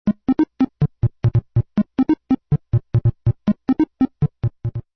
三角波の使用例 (mp3/15kb)
三角波は、直線で表せる波形の中では、もっとも正弦波に近い「丸い」音の出るものの一つです。
triangle_demo.mp3